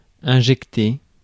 Ääntäminen
IPA: [ɛ̃.ʒɛk.te]